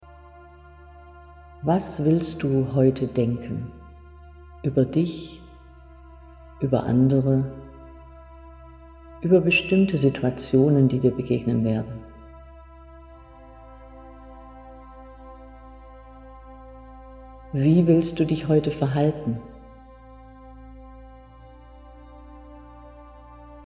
Morgenmeditation
Hoerprobe_Morgenmeditation.mp3